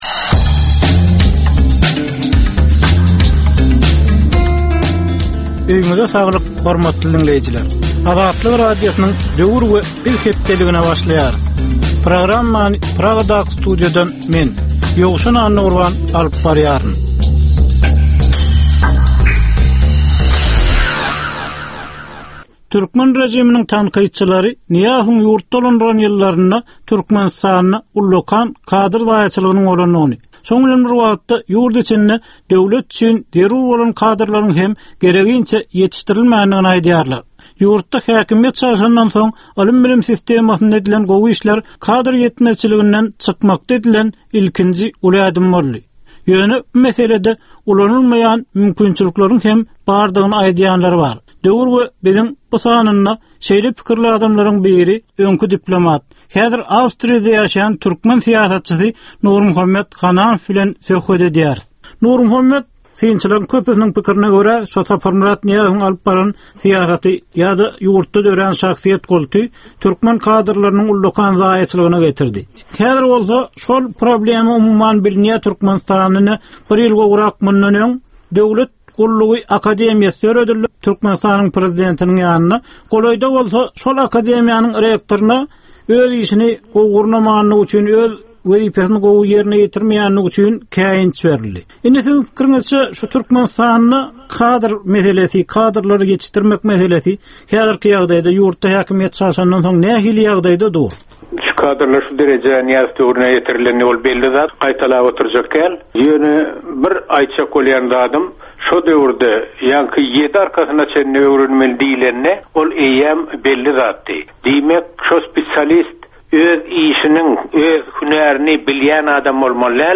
Türkmen jemgyýetindäki döwrüň meseleleri. Döwrüň anyk bir meselesi barada 10 minutlyk ýörite syn-gepleşik. Bu gepleşikde diňleýjiler, synçylar we bilermenler döwrüň anyk bir meselesi barada pikir öwürýärler, öz garaýyşlaryny we tekliplerini orta atýarlar.